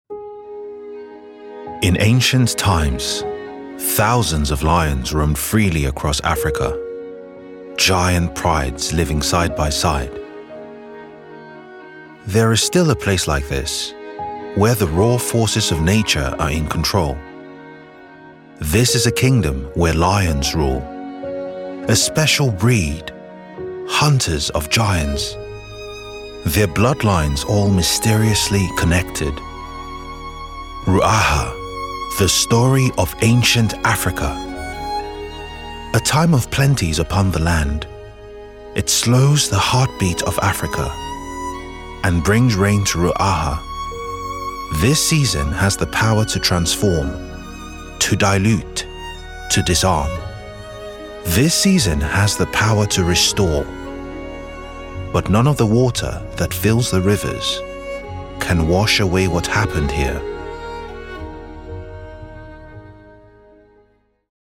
20s-40s. Male. African/Black British.
Documentary